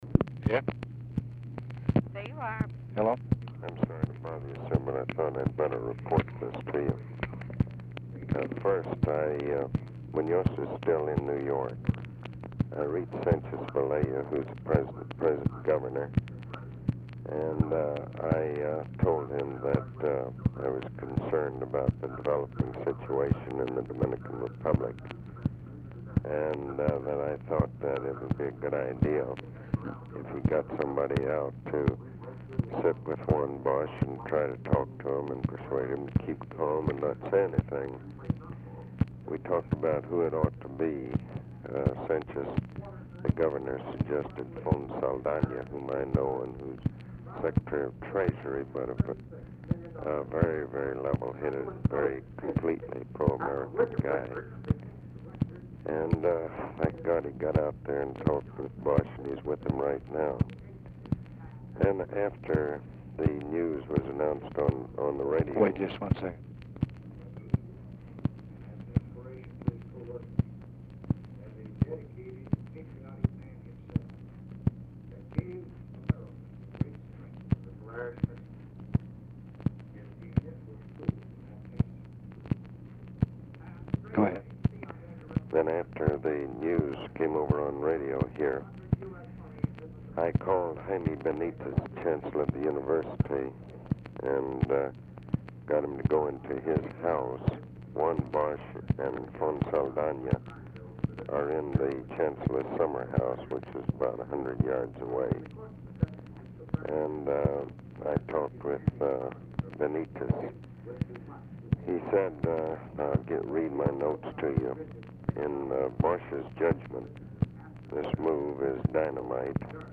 TV AUDIBLE IN BACKGROUND; LBJ INTERRUPTS FORTAS AT TIMES TO LISTEN TO BROADCAST
Format Dictation belt
Specific Item Type Telephone conversation Subject Communist Countries Defense Diplomacy Latin America